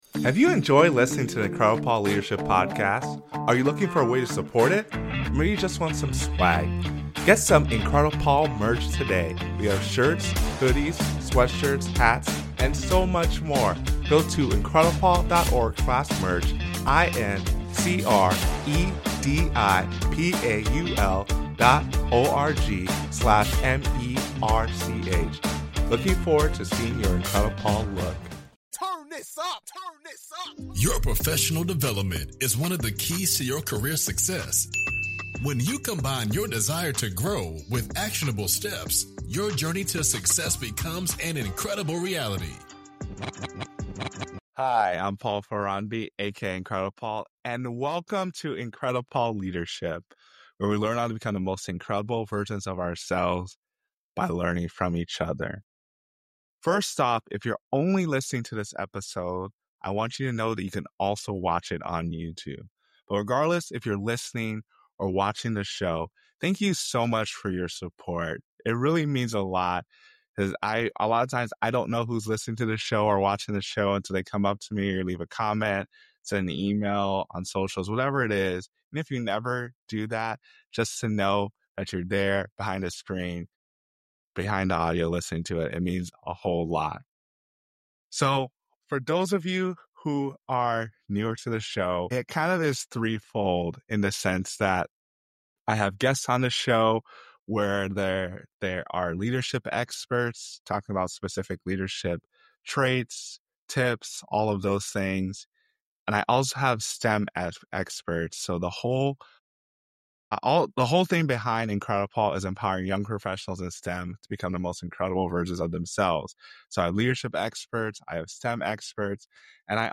The conversation emphasizes the significance of intentionality in leadership, the value of feedback, and the distinction between management and true leadership. In this conversation